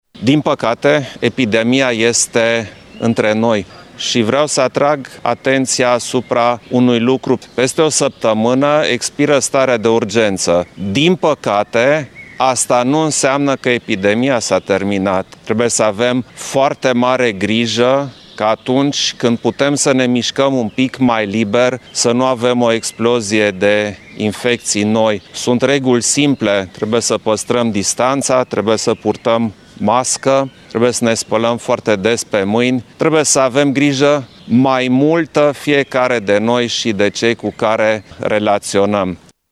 Declarațiile au fost făcute de președintele Iohannis astăzi cu prilejul vizitei sale la Tg.Mureș.